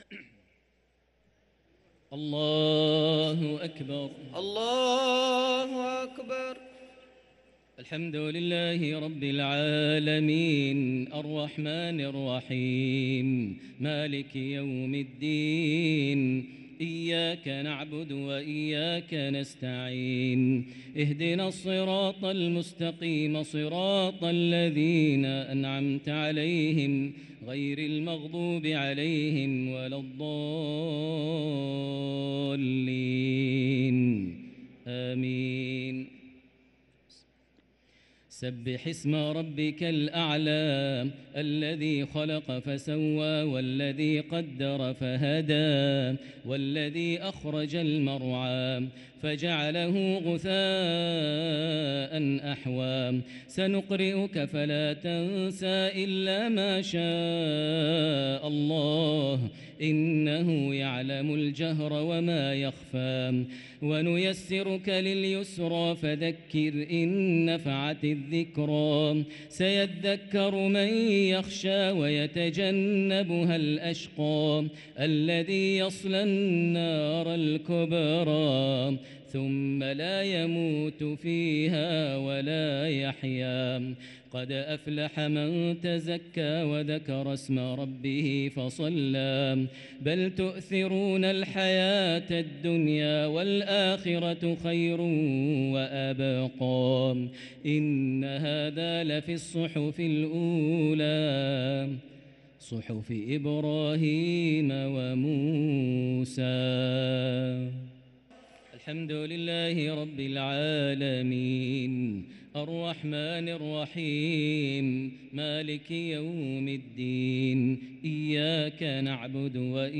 صلاة التراويح ليلة 14 رمضان 1444 للقارئ ماهر المعيقلي - التسليمتان الأخيرتان صلاة التراويح